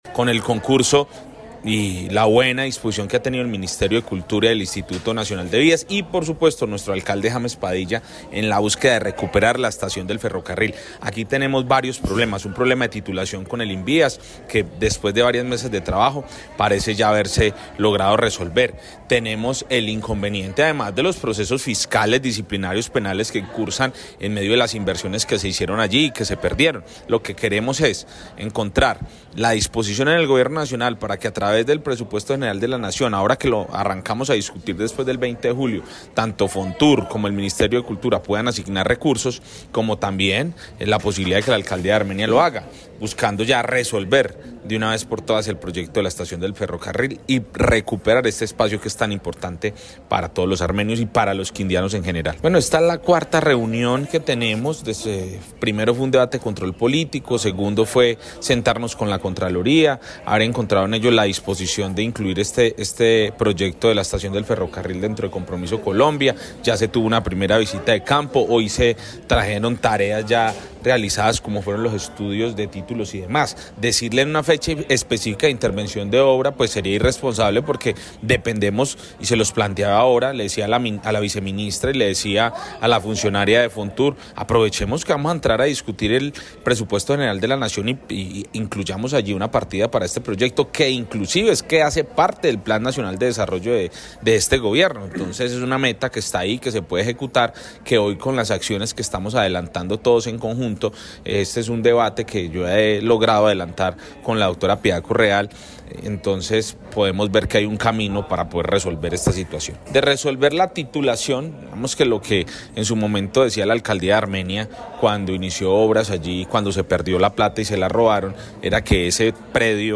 El representante a la cámara, John Edgar Pérez